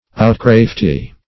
Outcrafty \Out*craft"y\